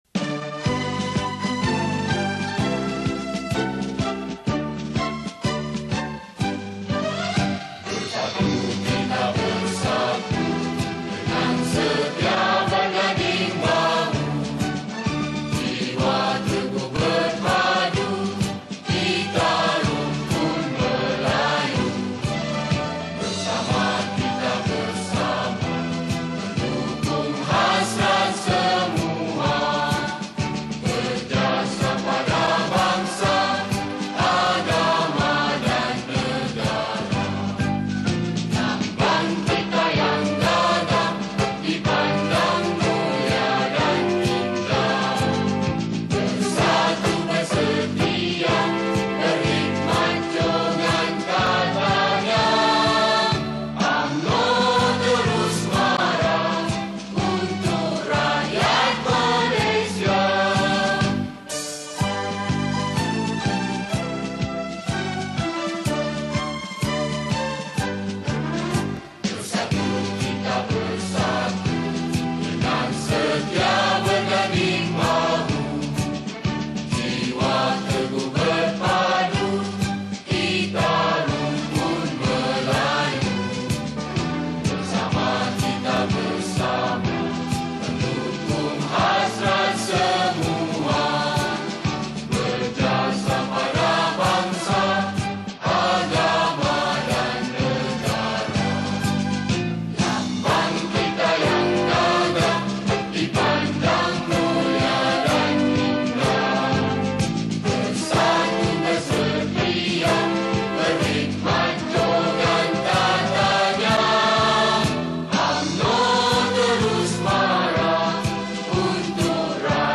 Malay Patriotic Song
Skor Angklung